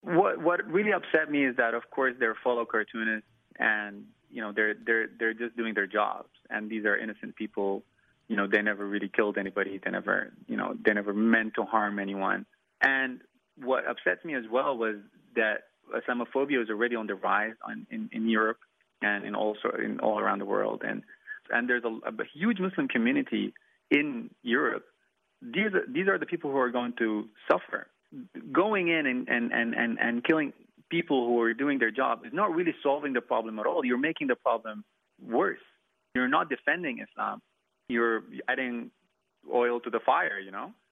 Sudanese cartoonist